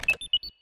Звуки авторизации